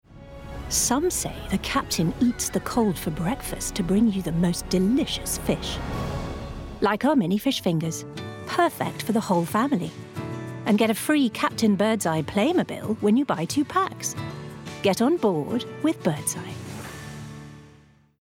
Birdseye Ad. Young Mum, Bright, Upbeat
Commerical Clips